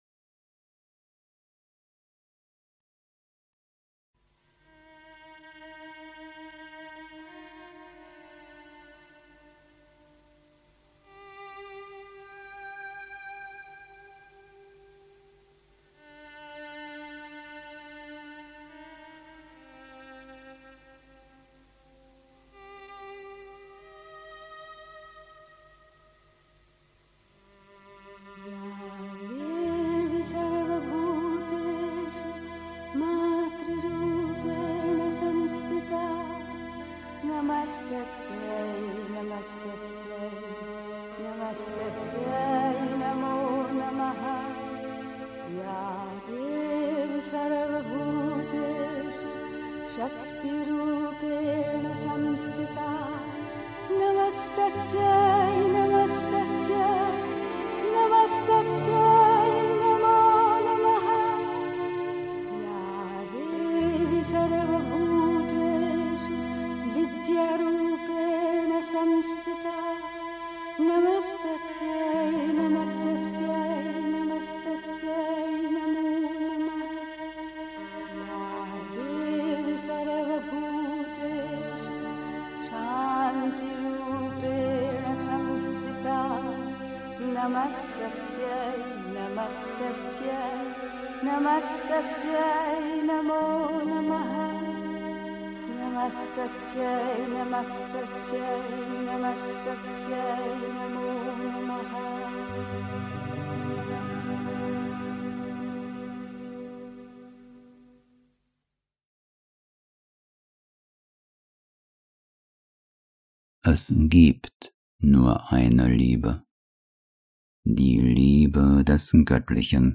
Es gibt nur eine Liebe (Die Mutter, CWM, Vol. 14, S. 124) 3. Zwölf Minuten Stille.
Meditation113.mp3